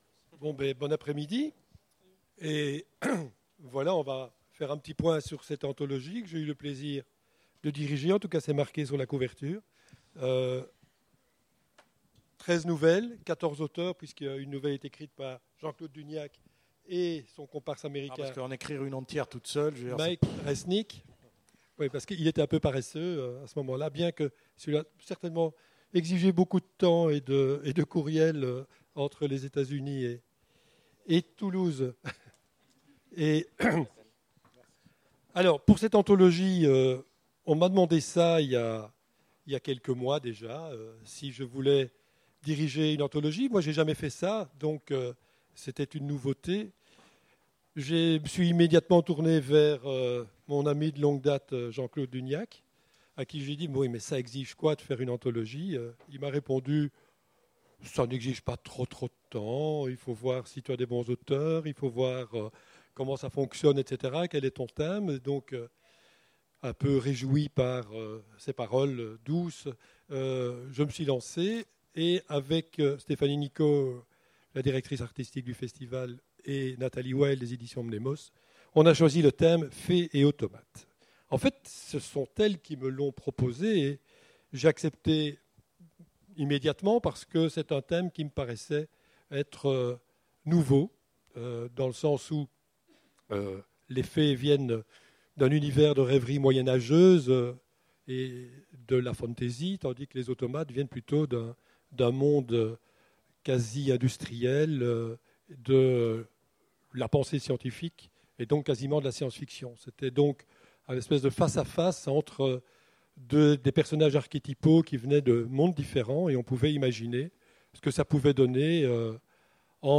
Imaginales 2016 : Conférence Fées & Automates